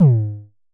Festival Kicks 21 - G#2.wav